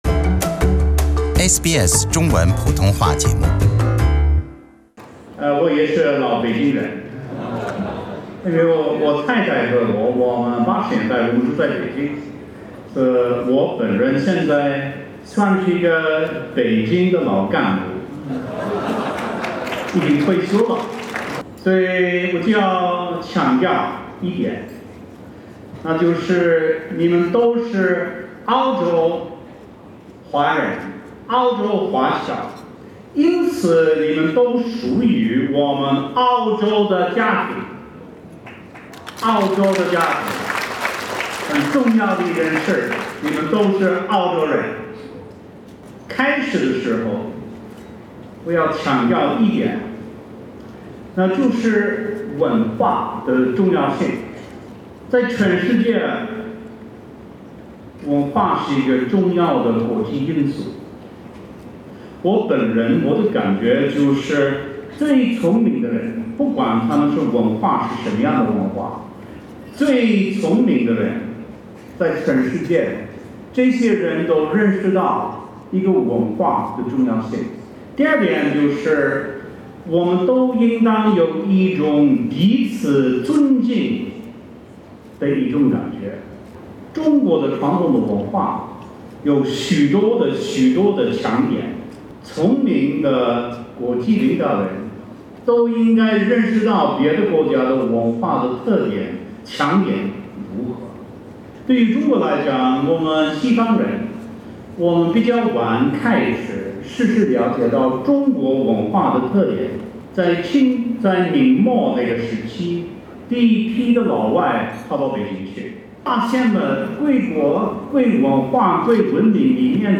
陆克文以一口流利的中文，在论坛中的演讲中从文化的重要性开始，论述了外交中两国了解彼此文化以及发现共同点，以及开展合作的重要性。